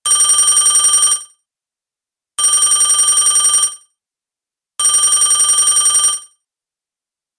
order_alert.mp3